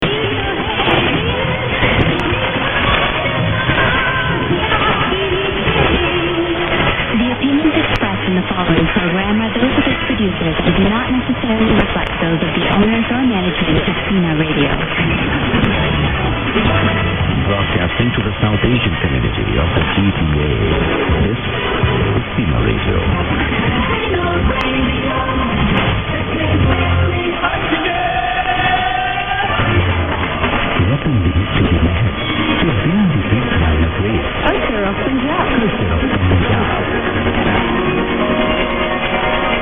111013_0600_1540_chin_g_and_e_ann.mp3